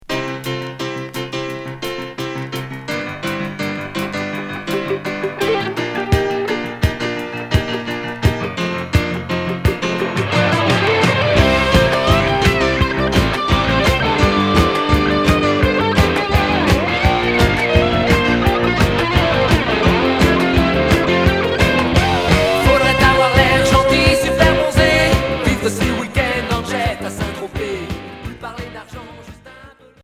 Rock enfantin Unique 45t retour à l'accueil